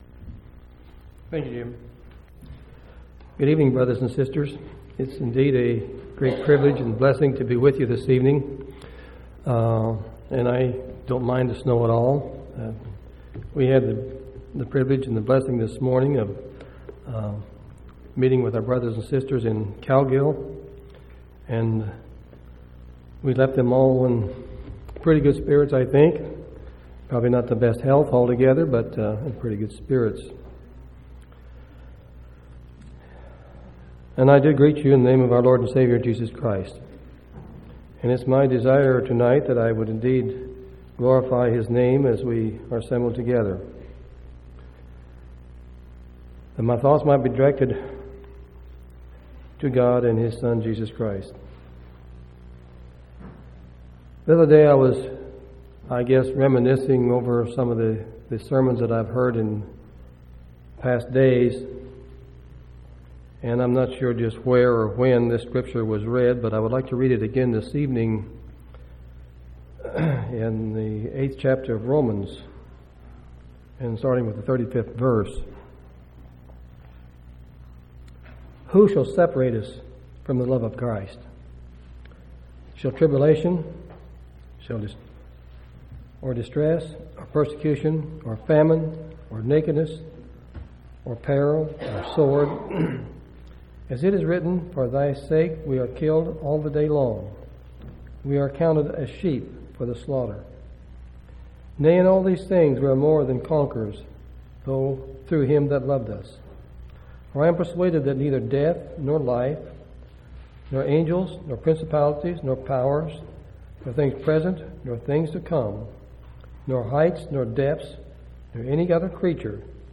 2/23/2003 Location: Temple Lot Local Event